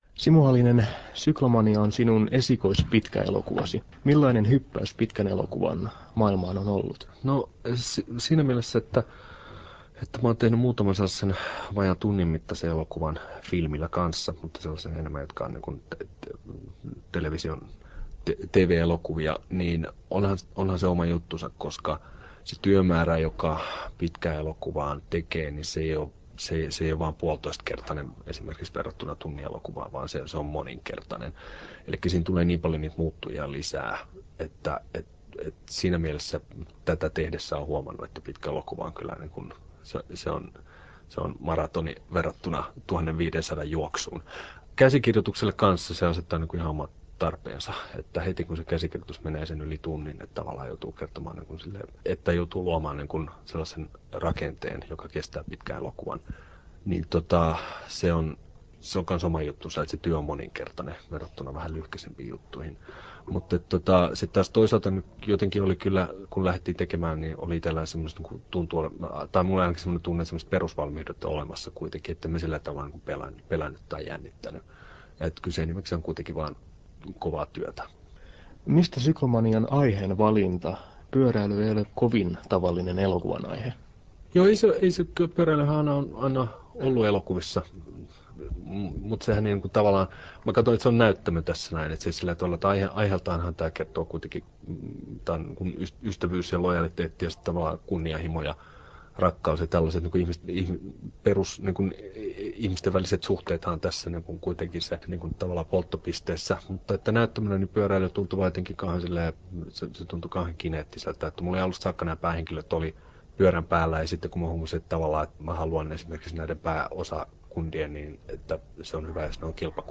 Nauhoitettu Turussa